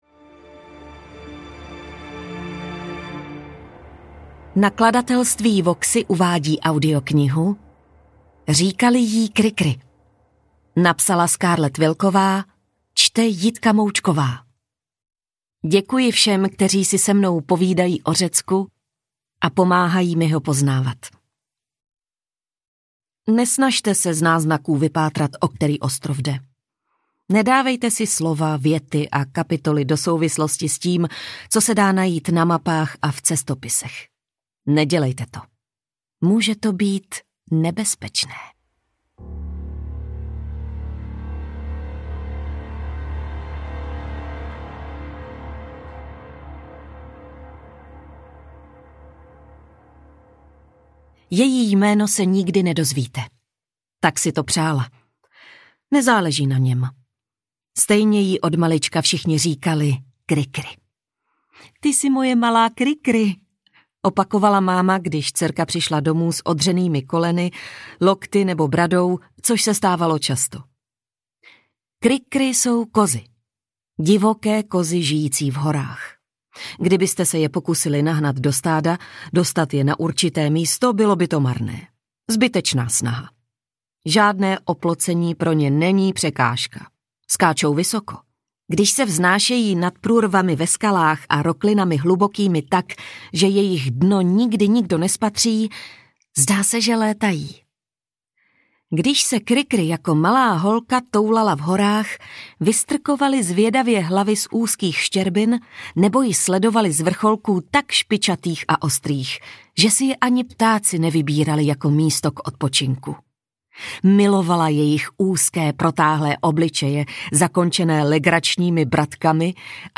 Říkali jí Kri-kri audiokniha
Ukázka z knihy